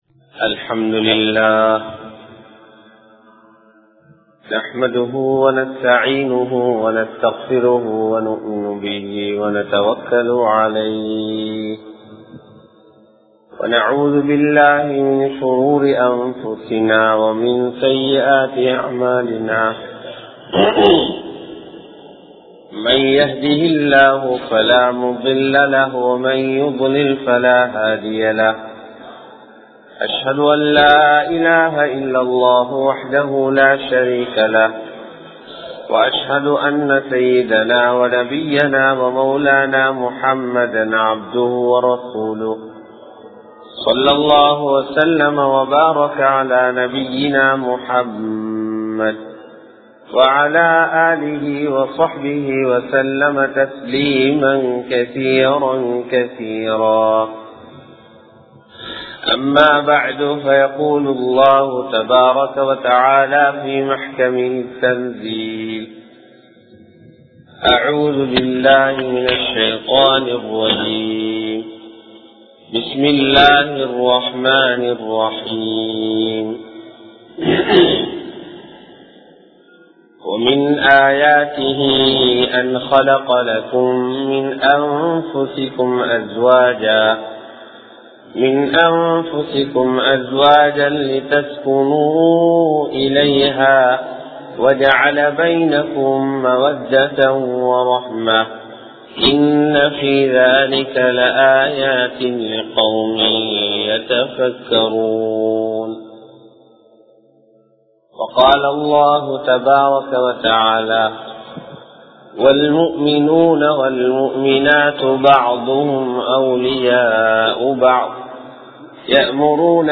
Thirumanathin Hikmathukkal திருமணத்தின் ஹிக்மத்துகள் | Audio Bayans | All Ceylon Muslim Youth Community | Addalaichenai
Uyanwaththa Noor Jumua Masjdh